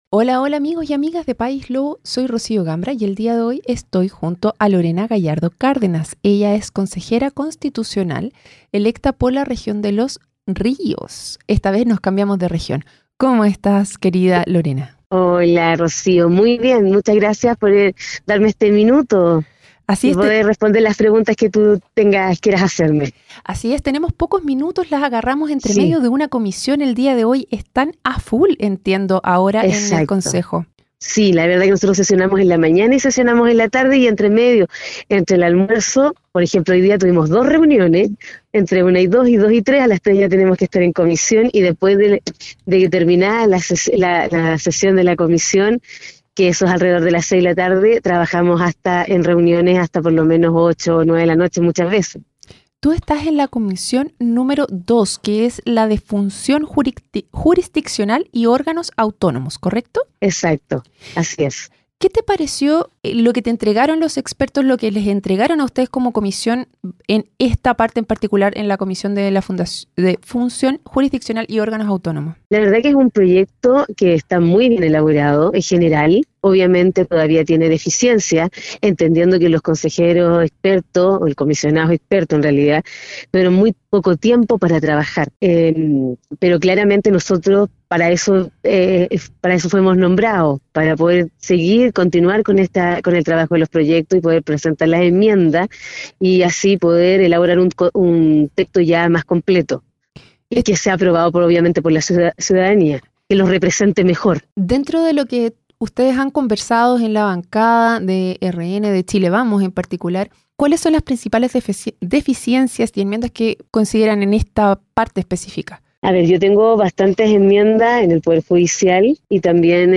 En su emisión más reciente, conversó con Lorena Gallardo Cárdenas abogada y política independiente. Integrante del Consejo Constitucional en representación de la 12a Circunscripción, Región de Los Ríos.